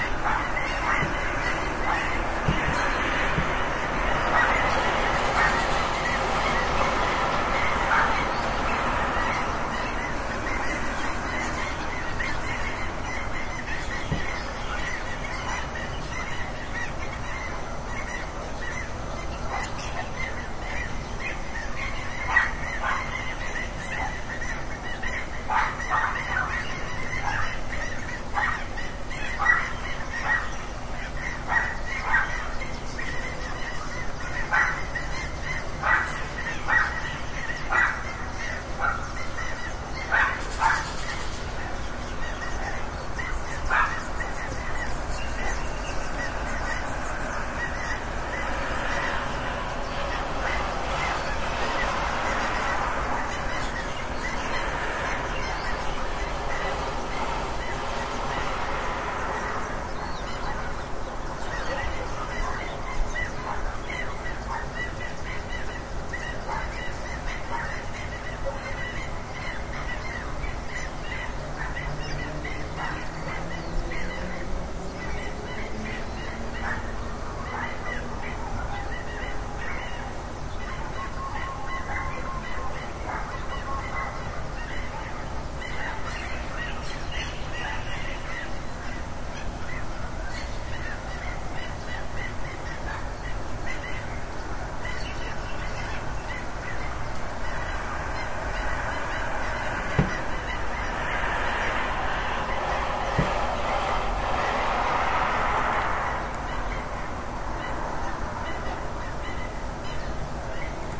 呱呱叫的鸭子
描述：在我邻居吠叫的喧嚣声中，经过交通和唱歌的鸟儿，你可以听到在我厨房窗外的宁静河里嘎嘎叫着一大群鸭子（我不知道这一天的种类）。我用迷你联想笔记本电脑的内置麦克风录制了这个。
Tag: 还会发出叽叽嘎嘎 嘎嘎鸭 嘎嘎叫 鸭子 嘎嘎